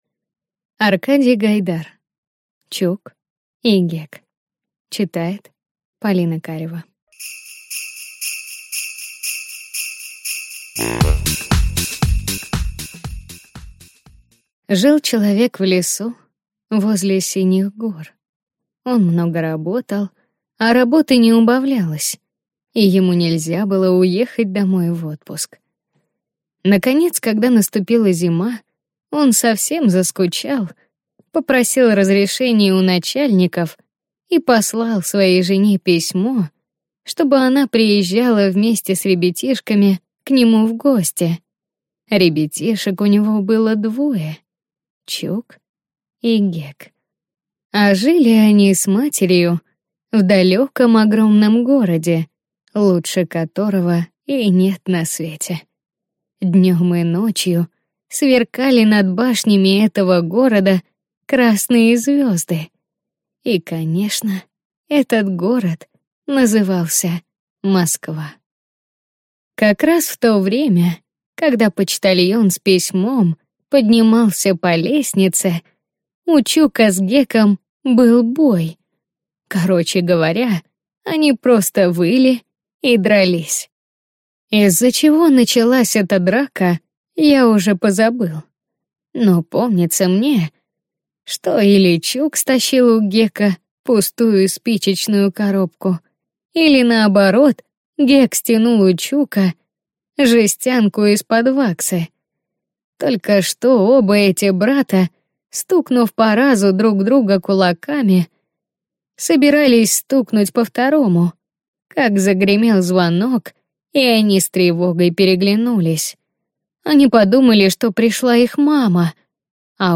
Аудиокнига Чук и Гек | Библиотека аудиокниг